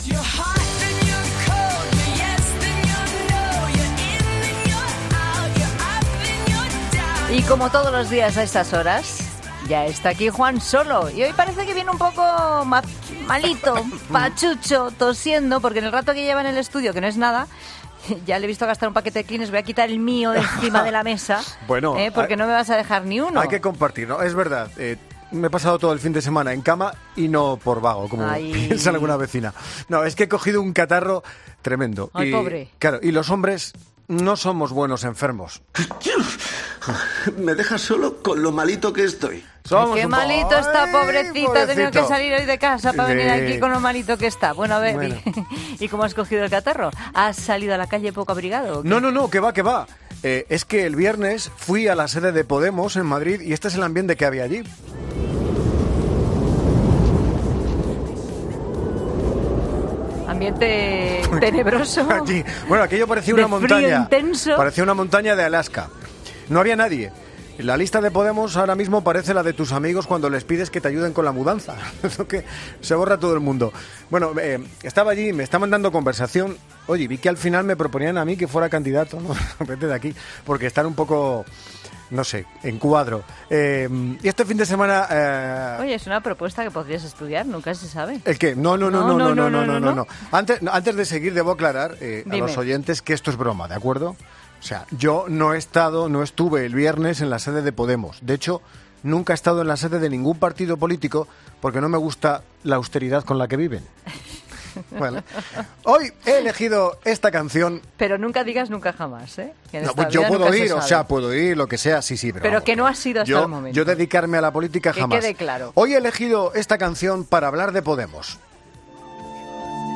'La Tarde', un programa presentado por Pilar Cisneros y Fernando de Haro, es un magazine de tarde que se emite en COPE, de lunes a viernes, de 15 a 19 horas.